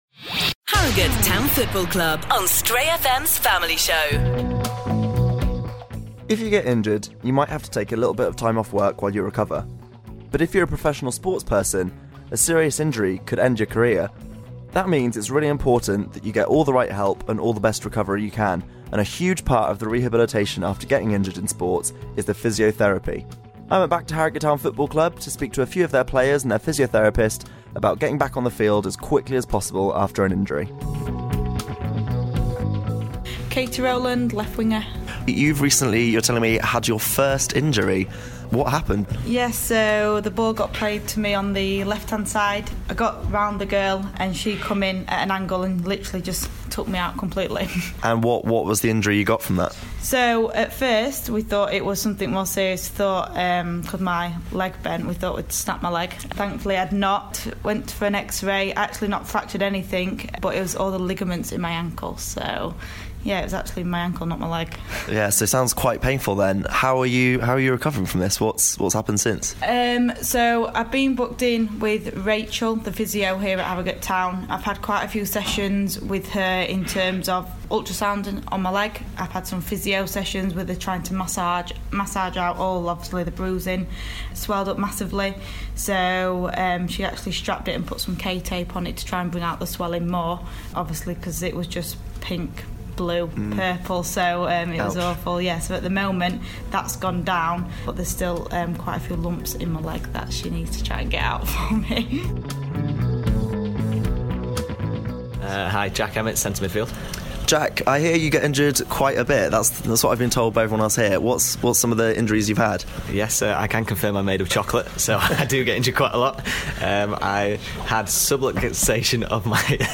Some of the players at Harrogate Town football club talk about their injuries, and how the right rehab can get them back on the pitch in a fraction of the normal time .